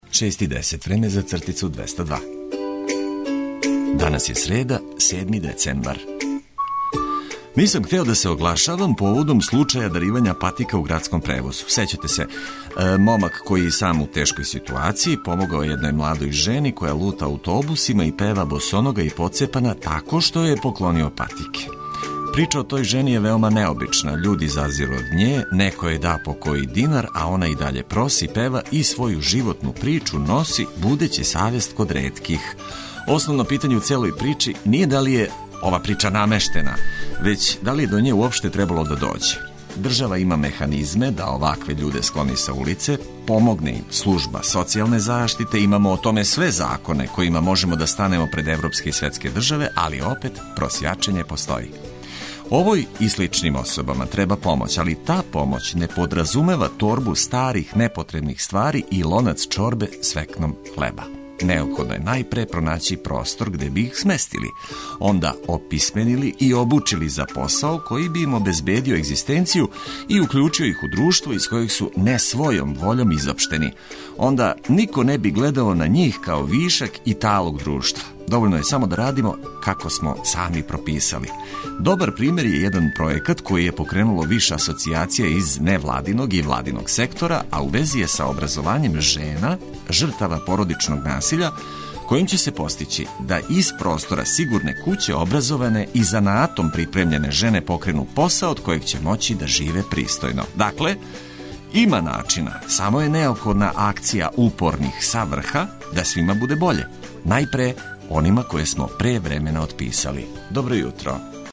Говорним сликама које улепшавају весели тонови растераћемо сан и закорачити у нови дан.